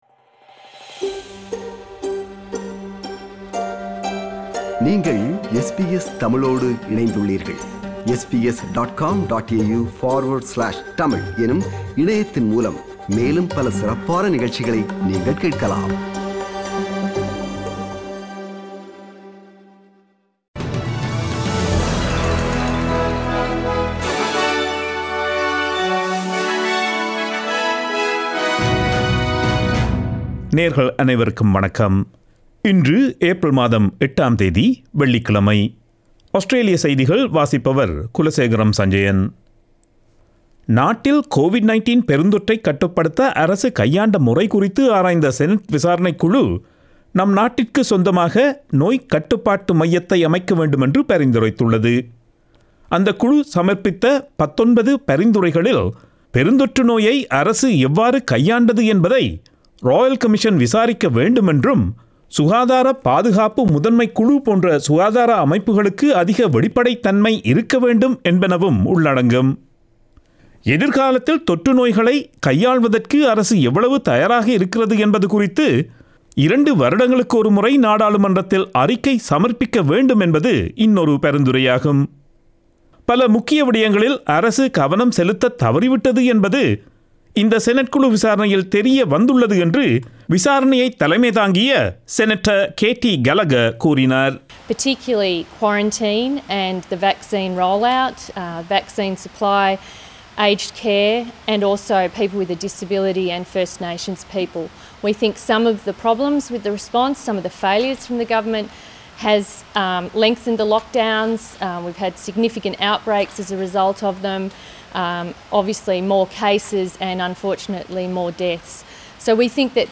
Australian news bulletin for Friday 08 April 2022.